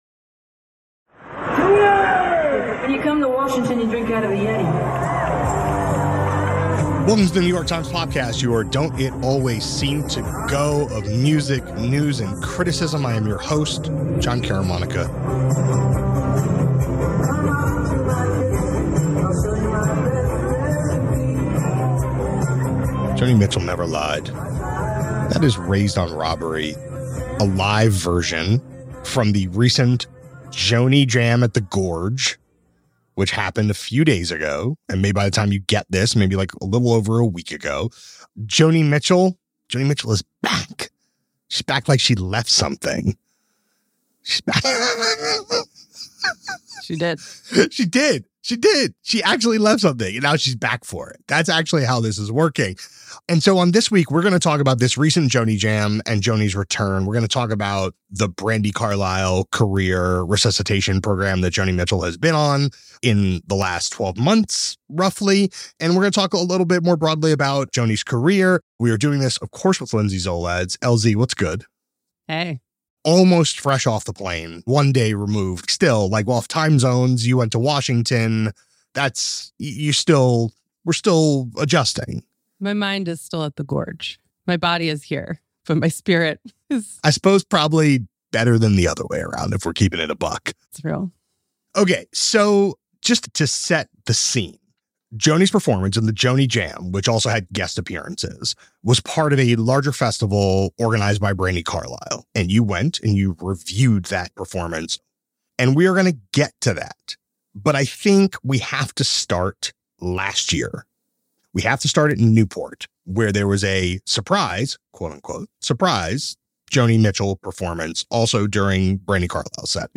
A conversation about how the singer-songwriter was received during her career peak, and how she is remaking her own music in her later years.